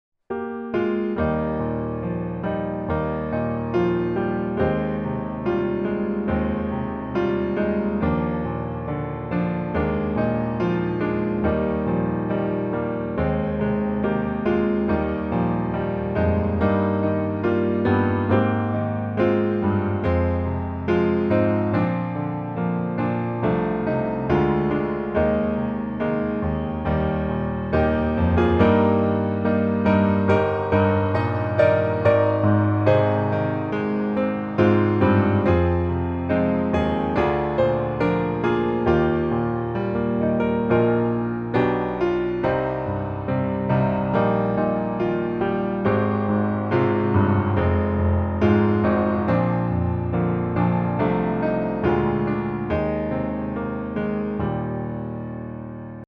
There’s a Hymn on My Radar. . .